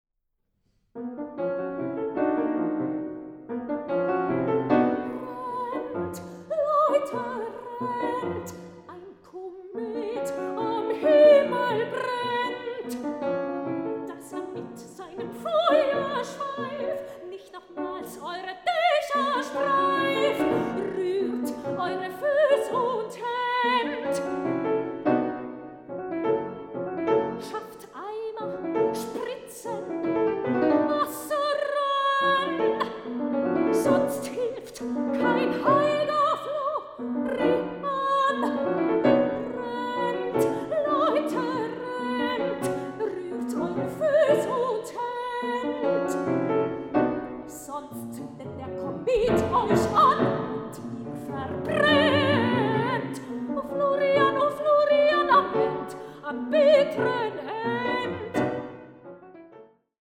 Sopran
Klavier
Aufnahme: Ackerscheune, Kulturstiftung Marienmünster, 2025